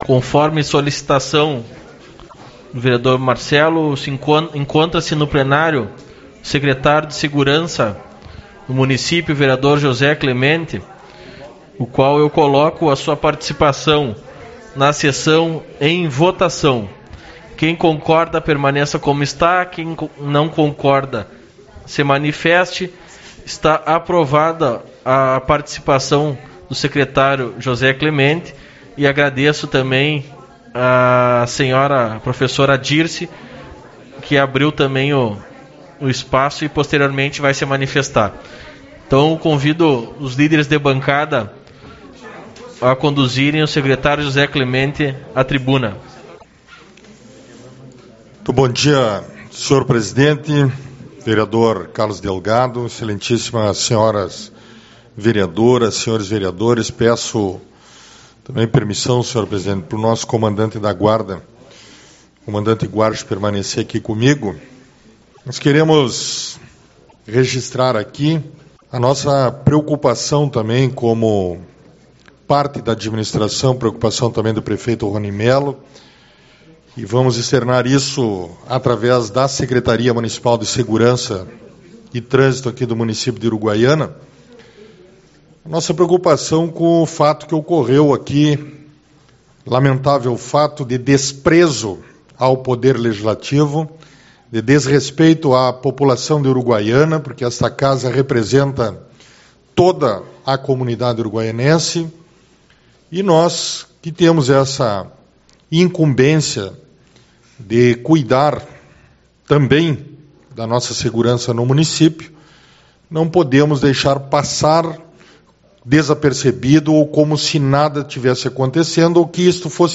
Você está aqui: Página Inicial / Comunicações / Galeria de Áudios / 2021 / Maio / 06/05 - Reunião Ordinária / Parte 3-Sec.